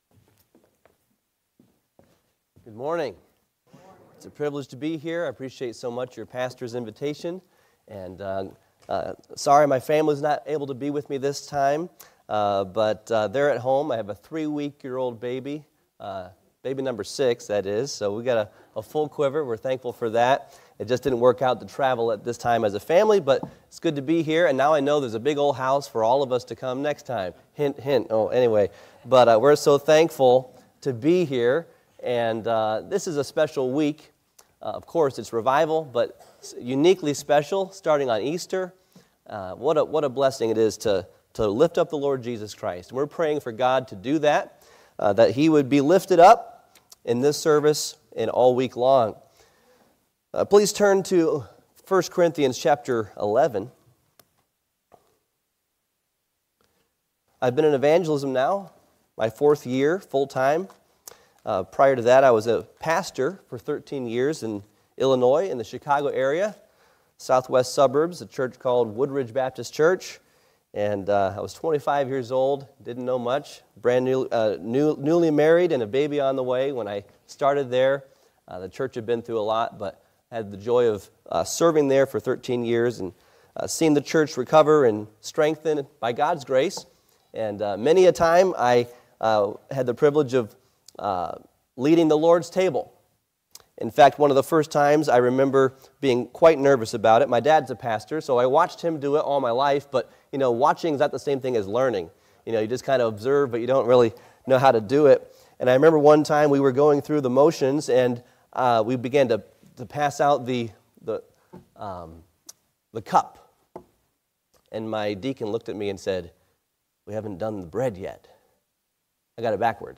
Series: 2025 Spring Revival Passage: I Cor. 11 Service Type: Sunday AM